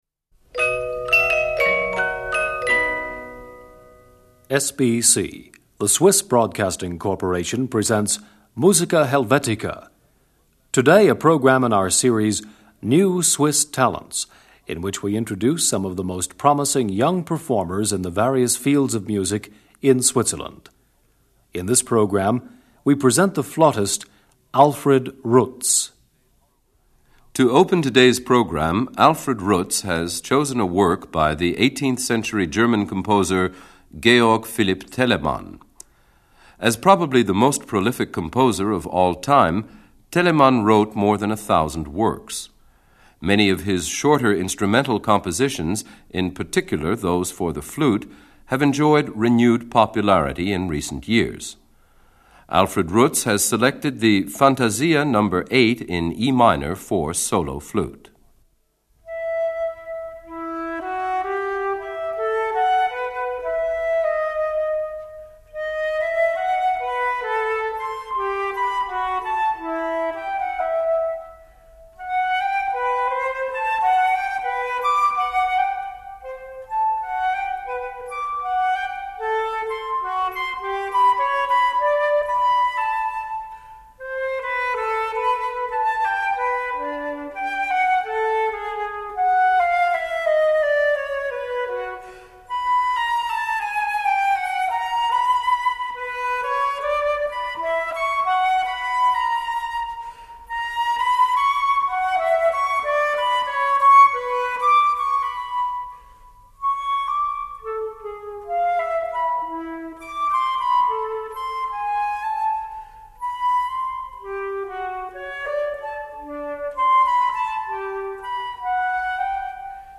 Georg Philip Telemann: Fantasia no. 8 in E minor for solo flute (Largo-Spiritoso-Allegro).
Live recording on the occasion of the Soloists’ Competiton of the Swiss Musicians’ Association in La-Chaux-de-Fonds on 29 August 1981. 4.
Symphony Orchestra of the Italian-Swiss Radio.